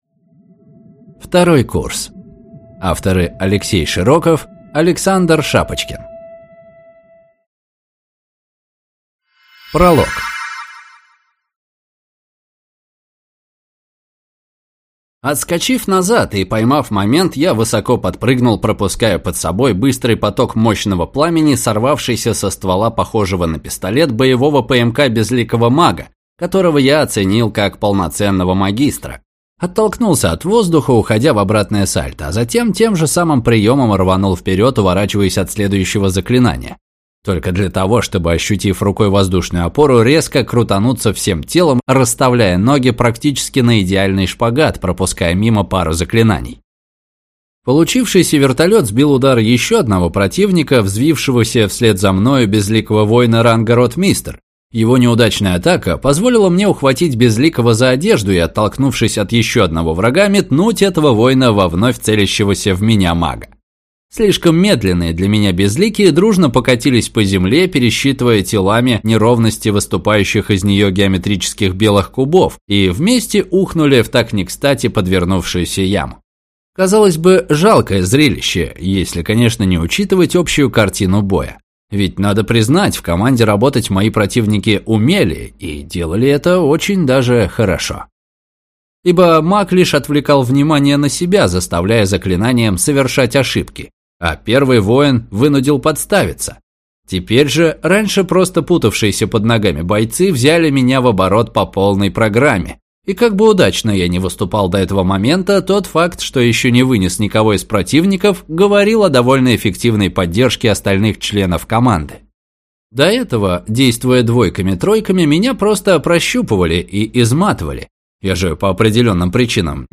Аудиокнига Второй курс | Библиотека аудиокниг